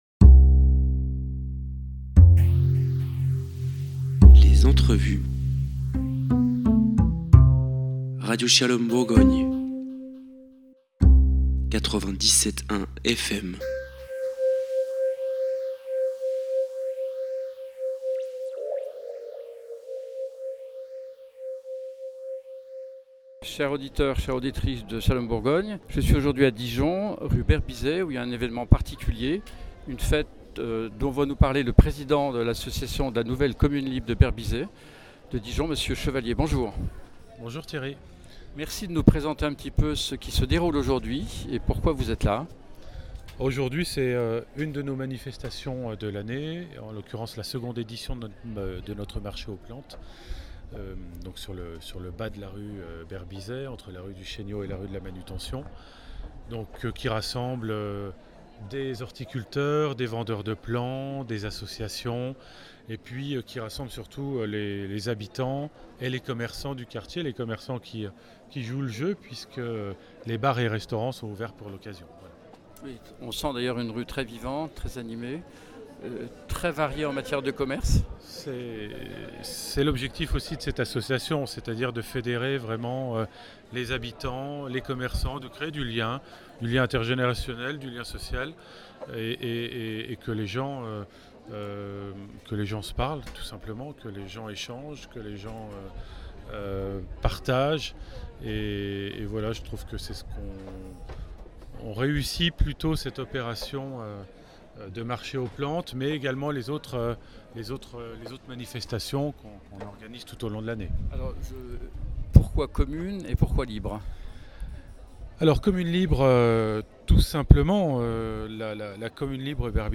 "Shalom Bourgogne" était présente au marché aux plantes rue Berbisey à Dijon.
Un grand soleil et une belle ambiance conviviale.